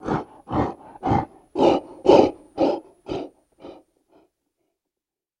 Animal Breath Large, Like Horse, Pig, Bear, Quick Labored